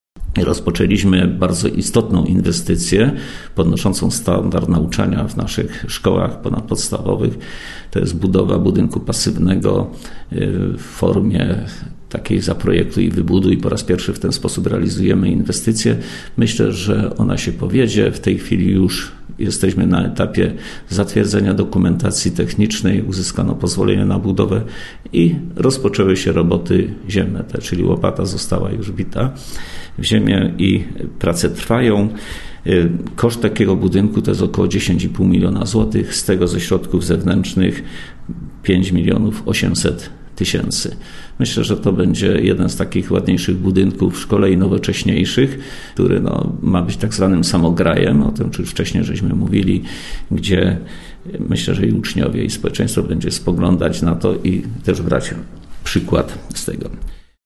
– mówił wicestarosta powiatu wieruszowskiego, Stefan Pietras.